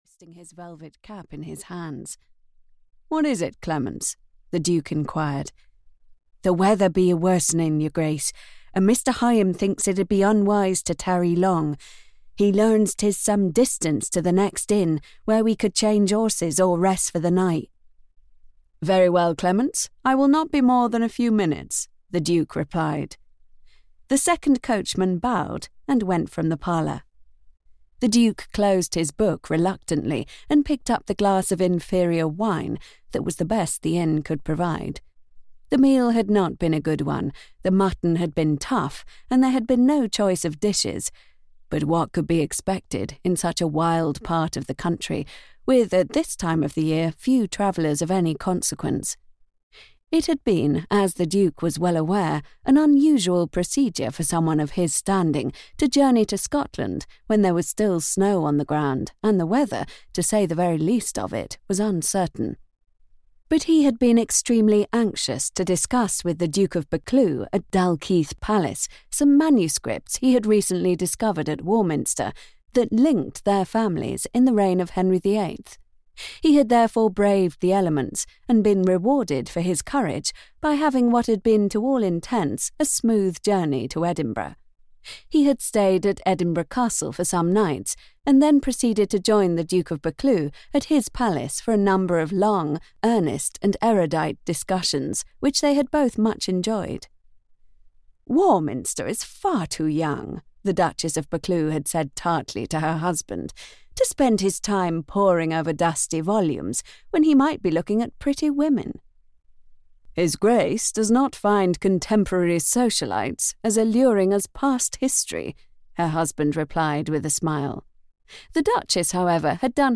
The Impetuous Duchess (EN) audiokniha
Ukázka z knihy